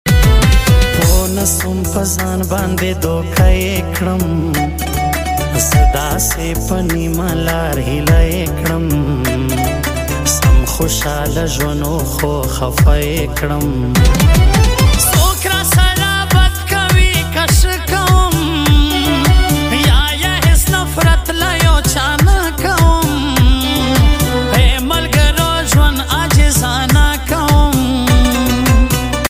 Pashto Song